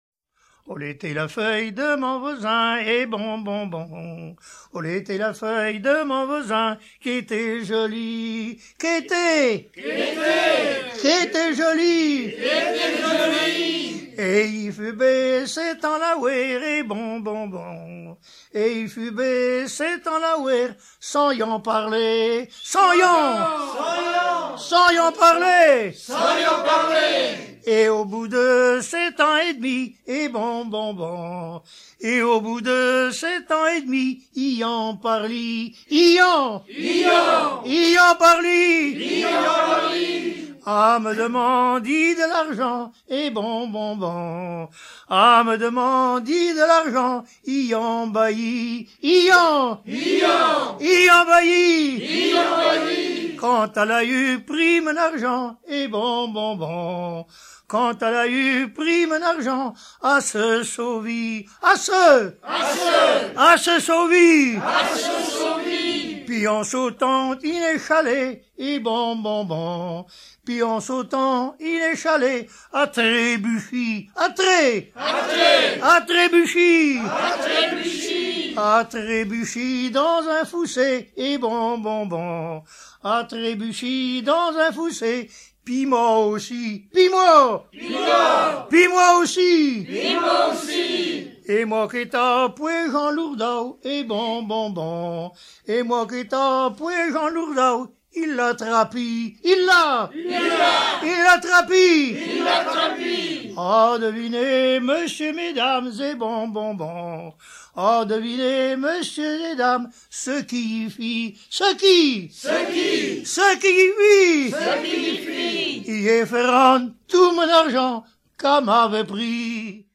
Langue Patois local
Genre laisse